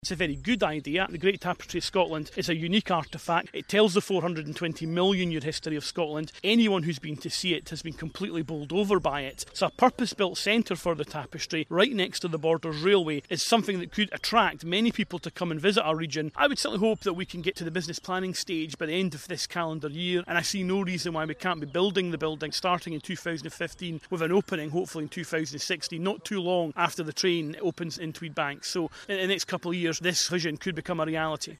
Leader of Scottish Borders Council, David Parker, spoke to Radio Borders News about plans to create a business case to bring the Great Tapestry of Scotland to a special new home in Tweedside Park.